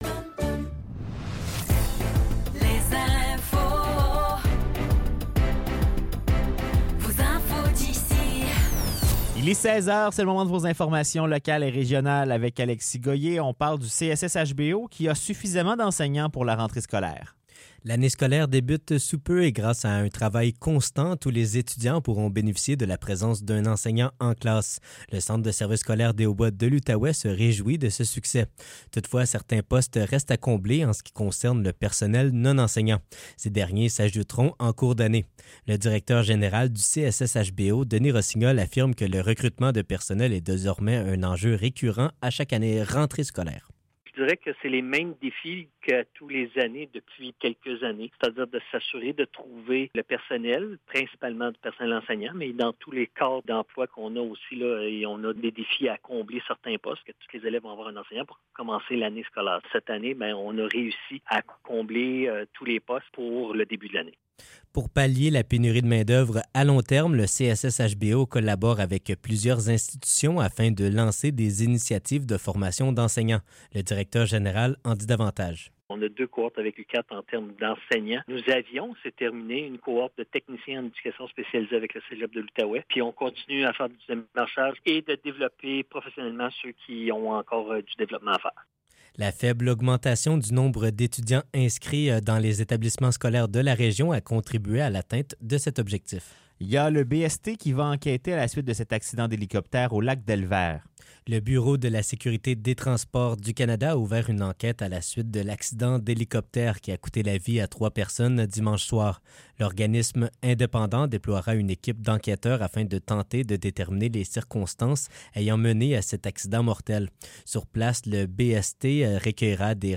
Nouvelles locales - 20 Août 2024 - 16 h